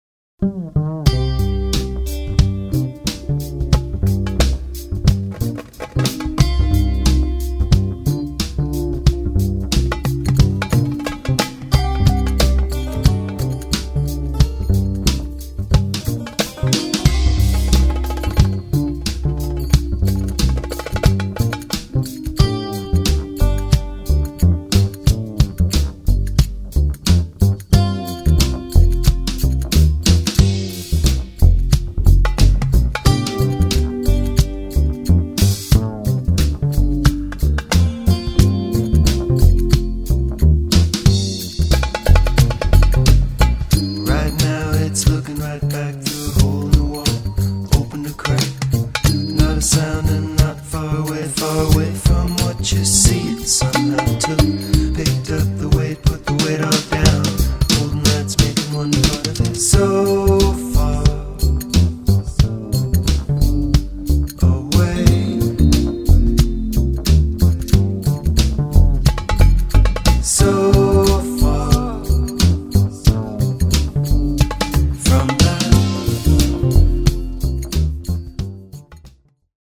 percussion from around the globe
poly-metric funk